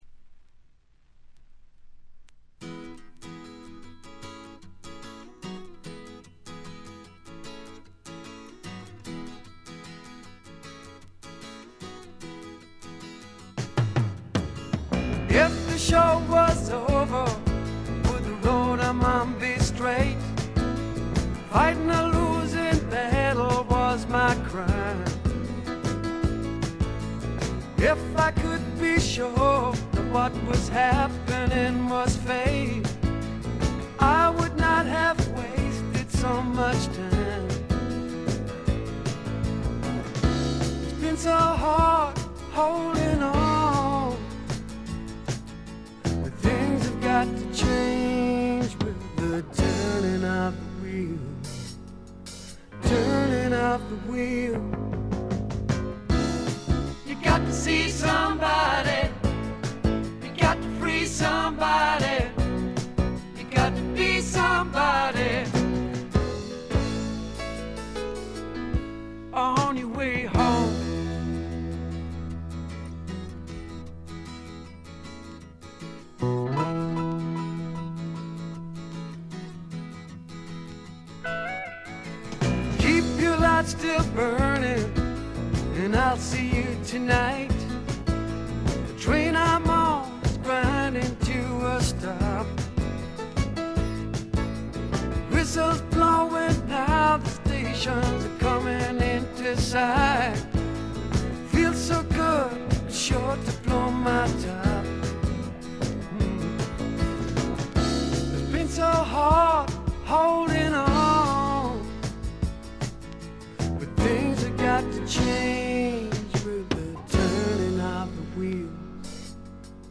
ところどころでチリプチ。
内容は身上である小気味良いロックンロール、軽快なフォークロック、メロディアスなポップ作等バラエティに富んだもの。
試聴曲は現品からの取り込み音源です。